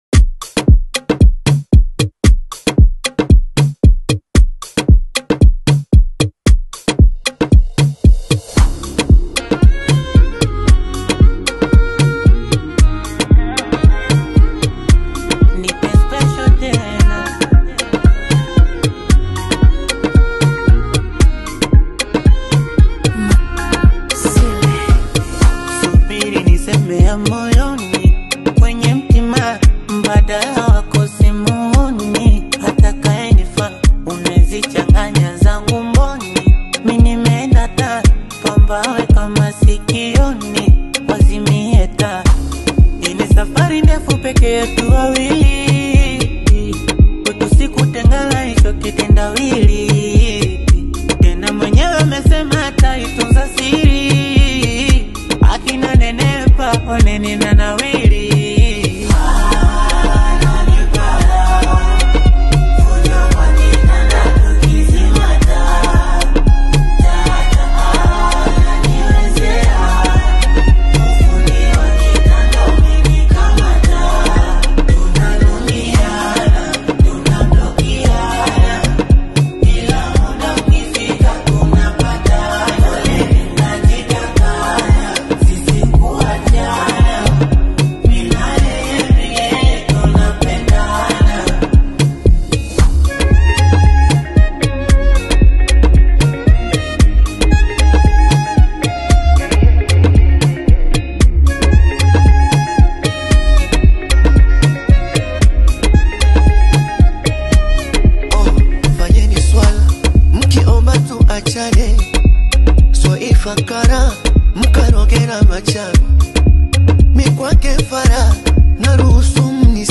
Genre: Bongo Flava